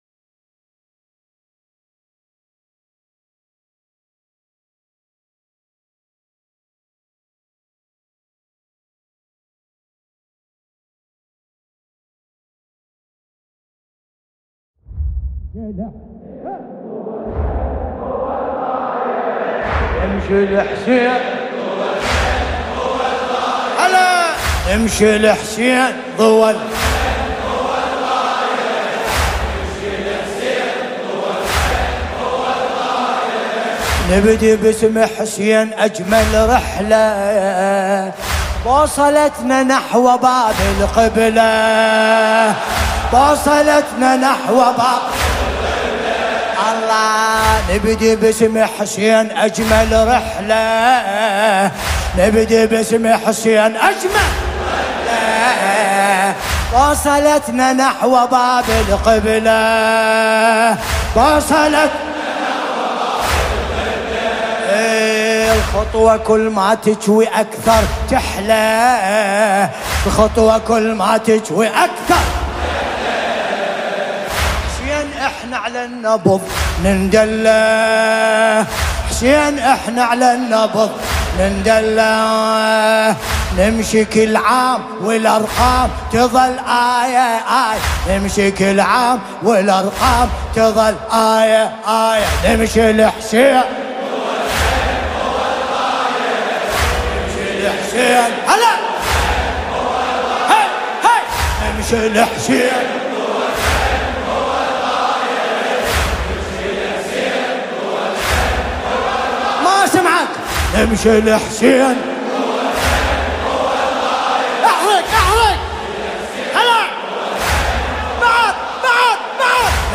مداحی اربعین عربی